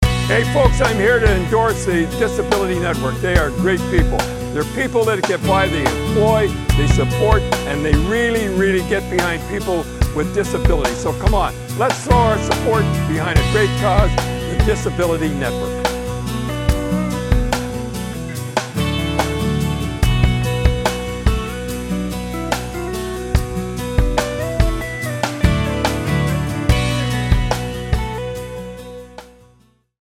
Shining-Light-30-sec-Don-Cherry-VO_1.mp3